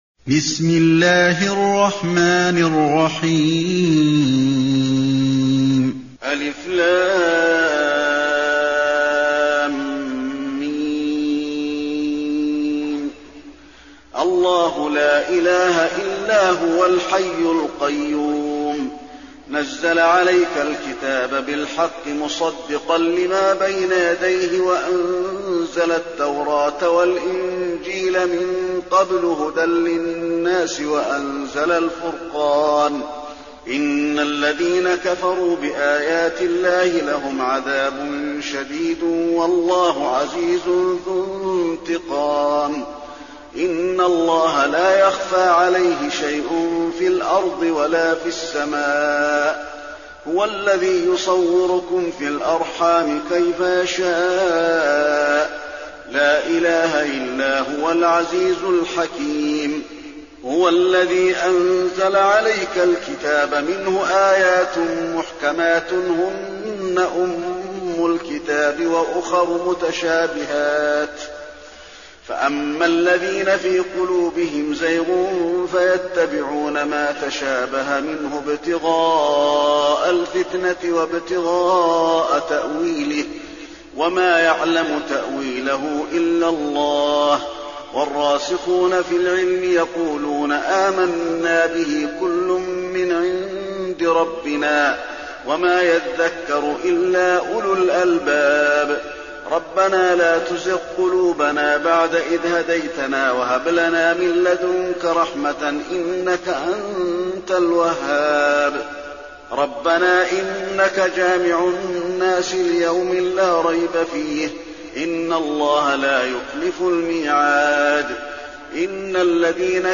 المكان: المسجد النبوي آل عمران